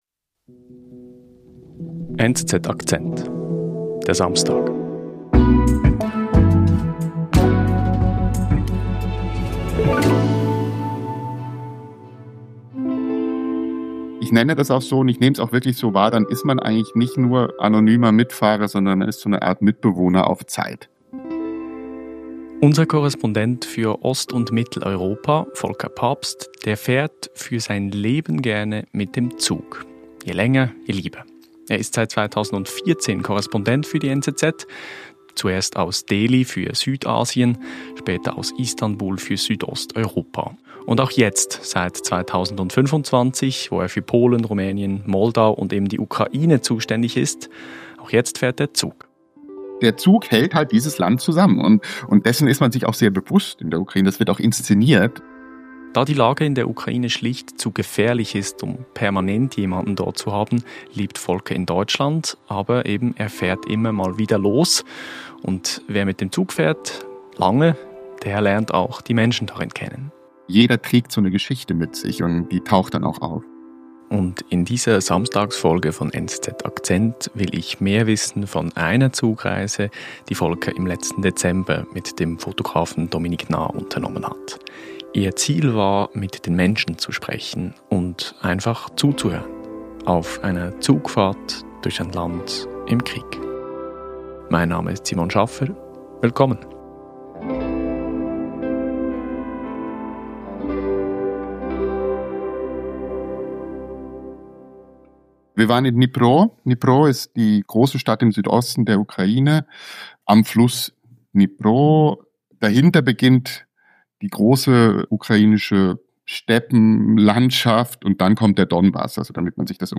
In den engen Abteilen entstehen Gespräche über Alltag, Angst und Hoffnung in einem Land immer noch mitten im Krieg.